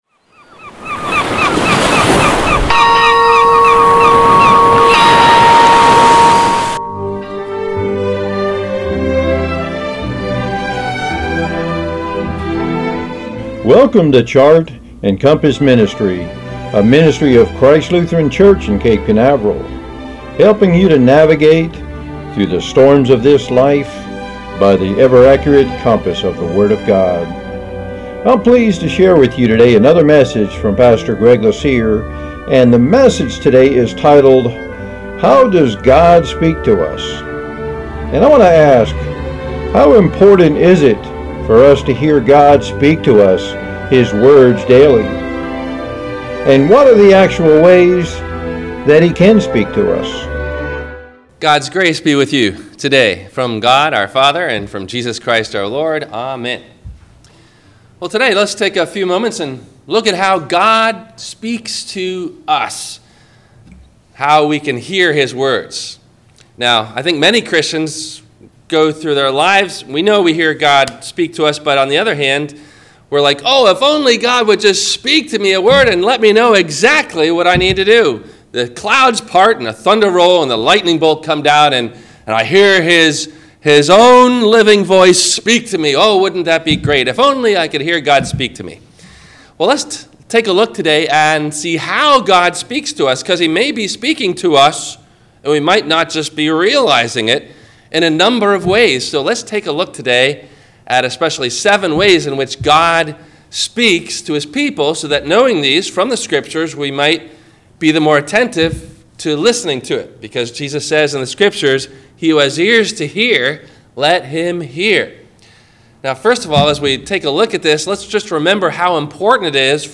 Should A Christian Fear Death? – WMIE Radio Sermon – March 16 2020